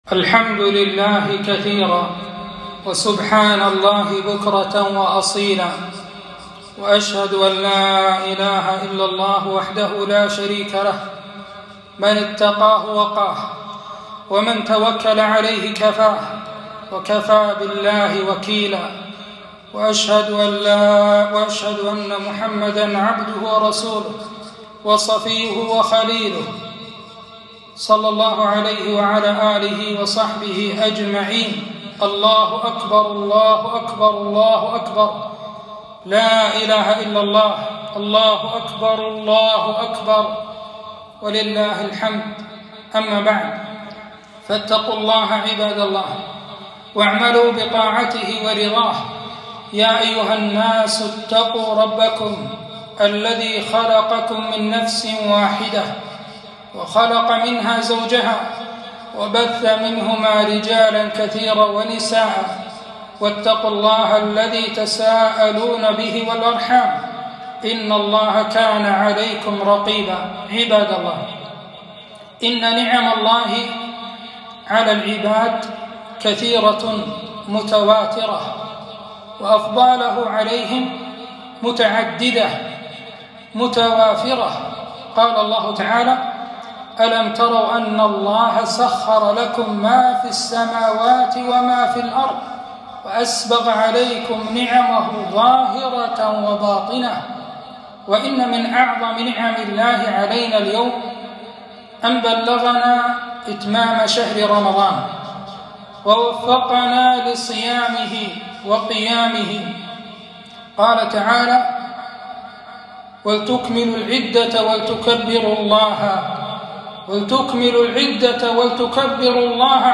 خطبة عيد الفطر 1443 - لايدخل الجنة قاطع رحم